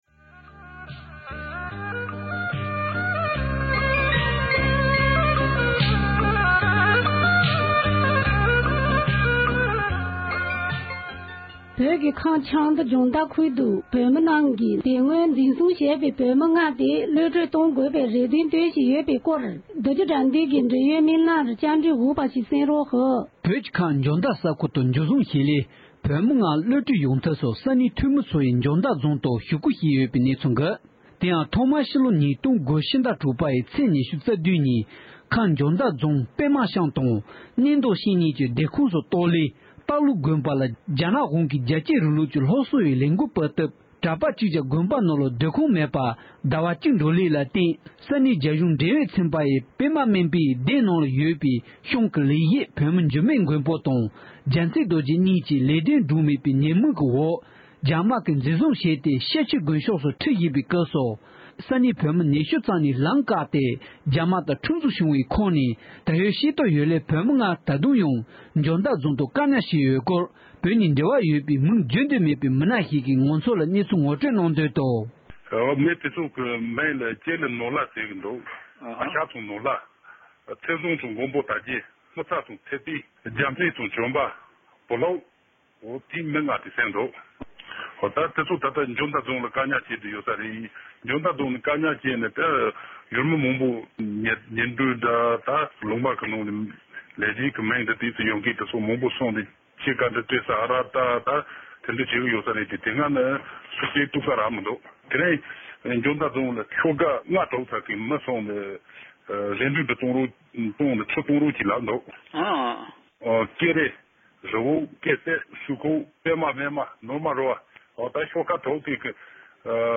འབྲེལ་ཡོད་མི་སྣ་ཞིག་ལ་བཀའ་འདྲི་ཞུས་པ་ཞིག